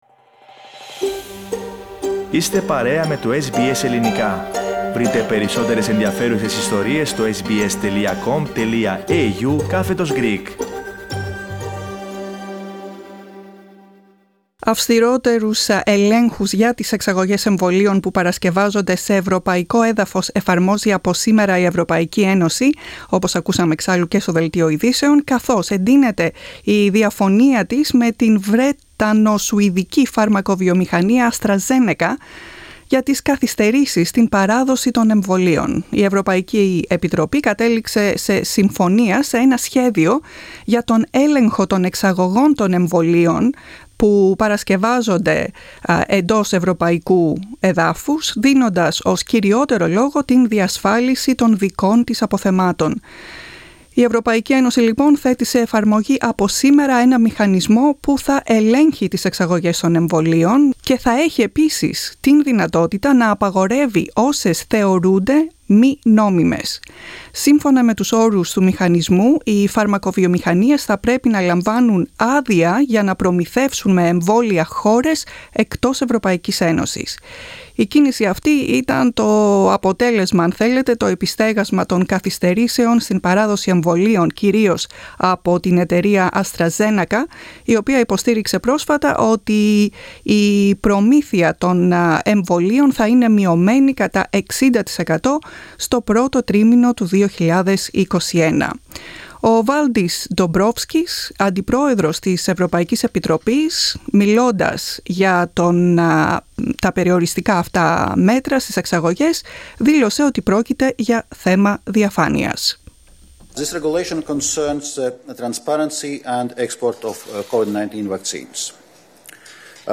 συζητούν το θέμα.